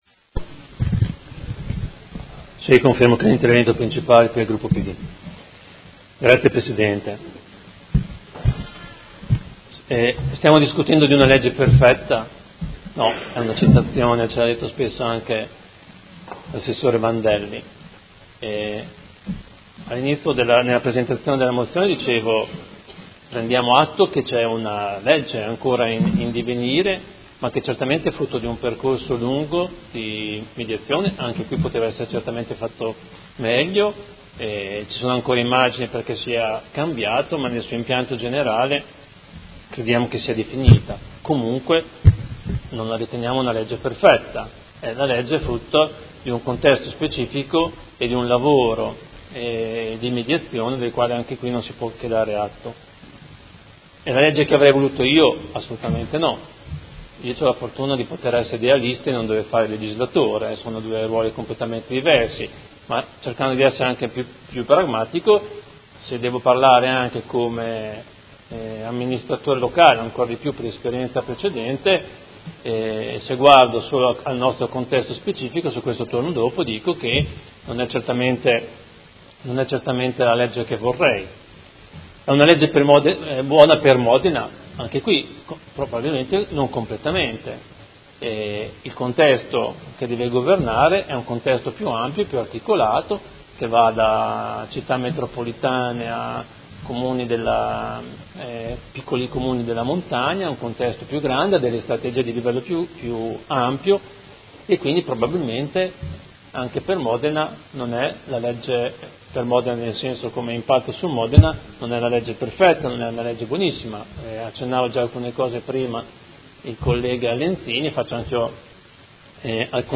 Seduta del 19/10/2017 Dibattito su Mozione 15321 e Ordine del giorno 157326